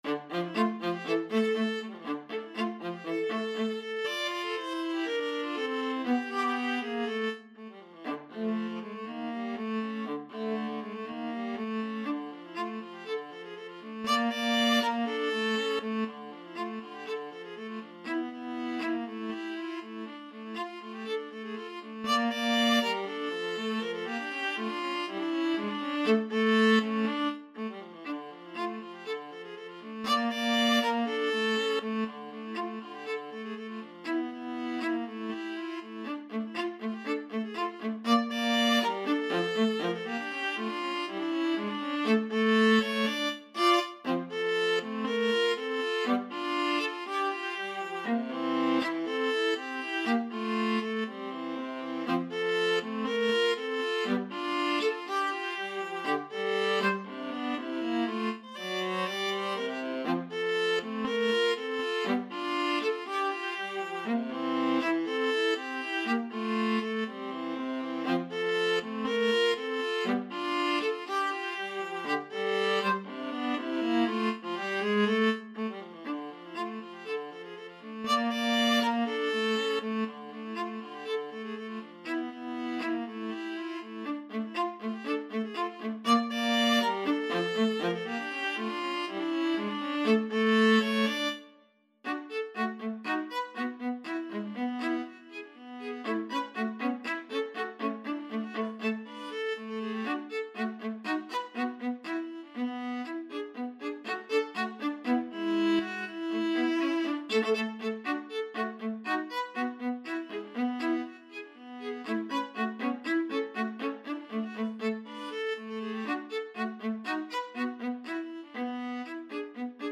Viola 1Viola 2
2/4 (View more 2/4 Music)
Allegretto Misterioso = 120
Classical (View more Classical Viola Duet Music)